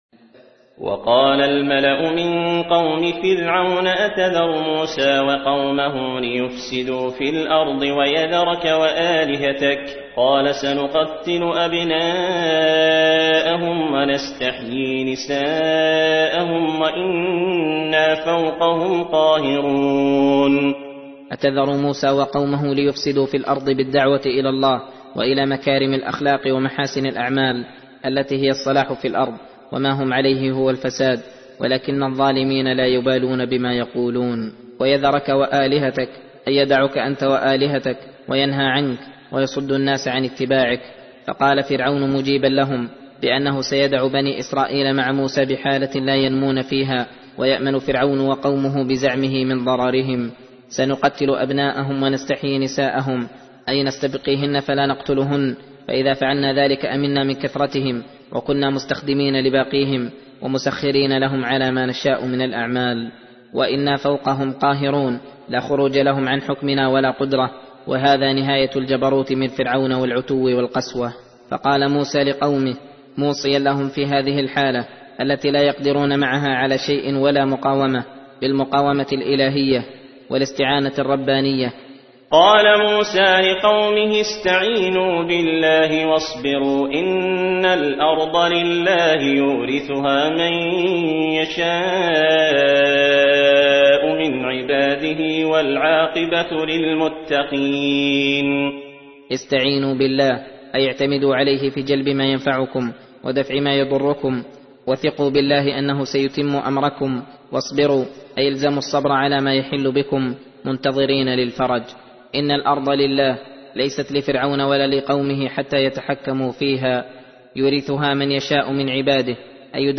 درس (7) : تفسير سورة الأعراف : (127-147)